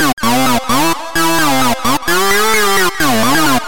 主厅合成器LEAD
Tag: 128 bpm House Loops Synth Loops 2.52 MB wav Key : Unknown